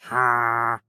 Minecraft Version Minecraft Version 1.21.5 Latest Release | Latest Snapshot 1.21.5 / assets / minecraft / sounds / mob / wandering_trader / no5.ogg Compare With Compare With Latest Release | Latest Snapshot